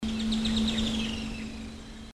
Curve-billed Reedhaunter (Limnornis curvirostris)
Life Stage: Adult
Province / Department: Buenos Aires
Location or protected area: Delta del Paraná
Condition: Wild
Certainty: Observed, Recorded vocal